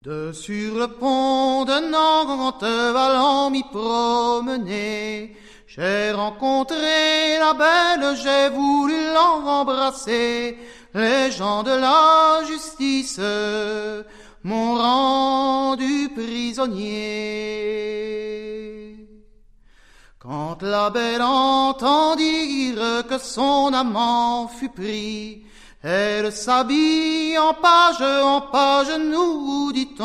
Musique : Traditionnel
Origine : Bretagne